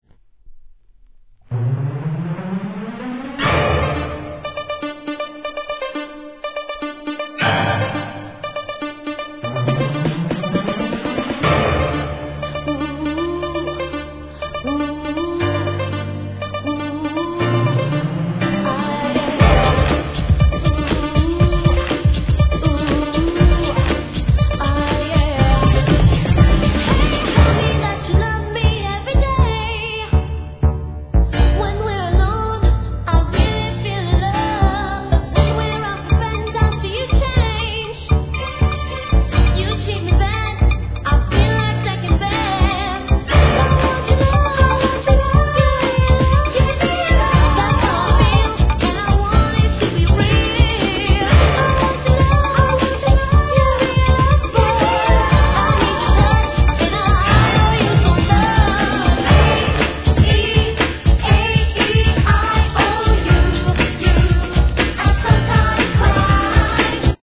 1. HIP HOP/R&B
POP HOUSE 12"  FREESTYLE ver.